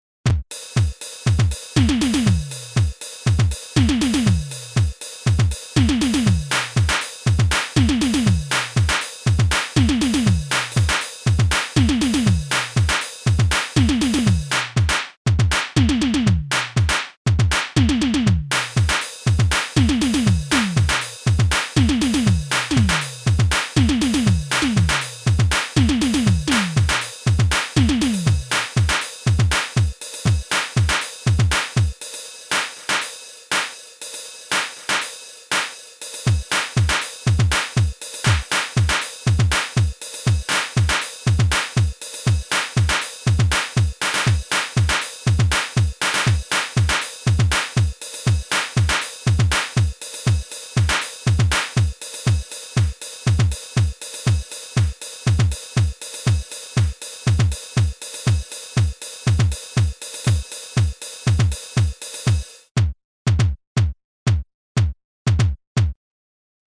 Compact portable digital drum machine based on 12 bits PCM samples with LCD matrix sequencer.
- the "DR220E" versin with a more Electronic-oriented drum sample set.
electro BD electro SN hihat
cowbell/slap hi/low/mid tom china cymbal / cup
demo AUDIO DEMO
REVIEW : "Rompler portable box, nice simmons-like sound, great source for sampling."